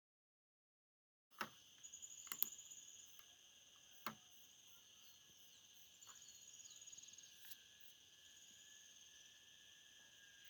Carpinterito Común (Picumnus cirratus)
Localización detallada: Villa Paranacito, Area Protegida Arroyo Las Palmas
Condición: Silvestre
Certeza: Vocalización Grabada